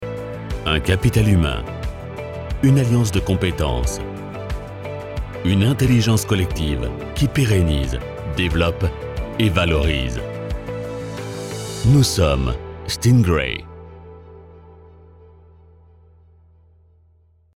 Vidéos d'entreprise
Promotions
Documentaires
Concernant mon matériel, je possède une cabine vocale et le légendaire micro Neumann U87.